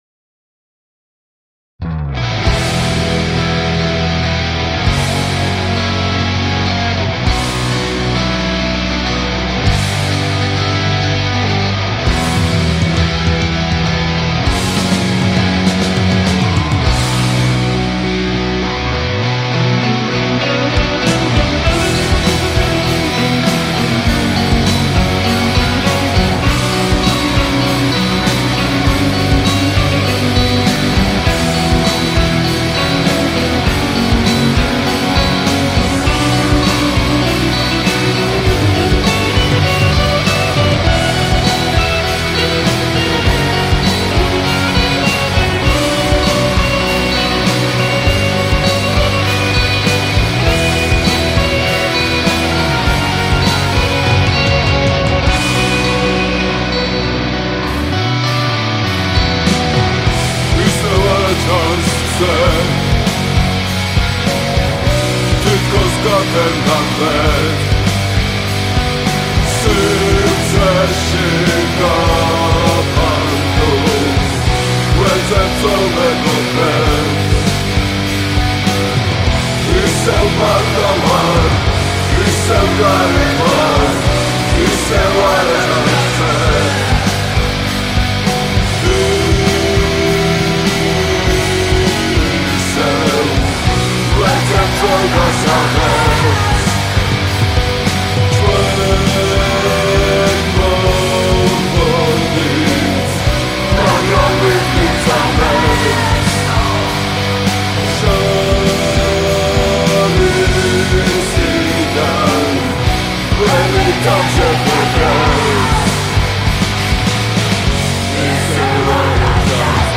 Here's a metal version if you really did like that.